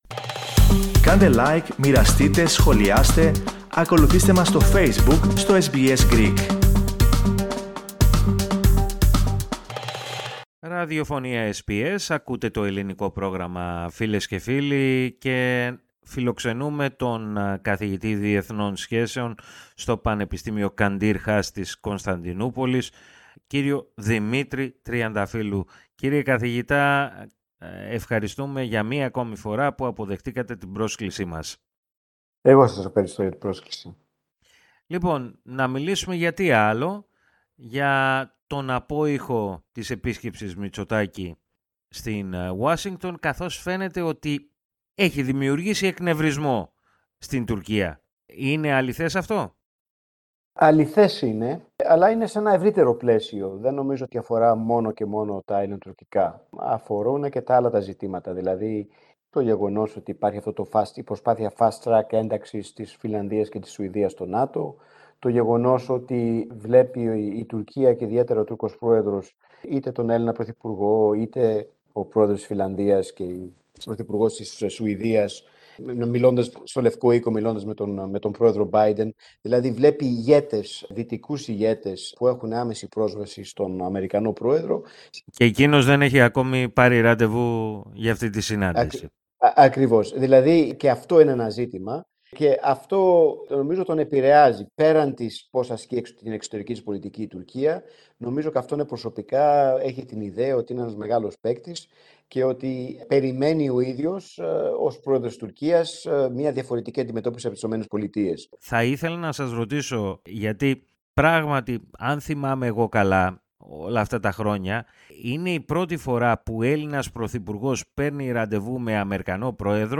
Ακούστε ολόκληρη τη συνέντευξη, πατώντας το σύμβολο στο μέσο της κεντρικής φωτογραφίας.